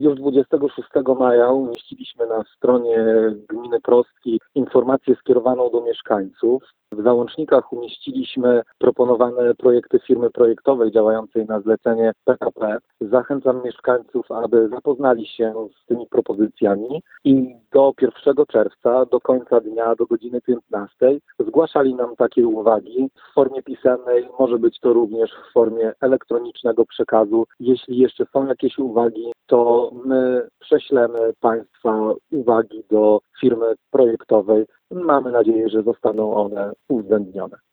Mimo to samorządowiec zachęca mieszkańców, aby swoje ewentualne uwagi wysłali do urzędu.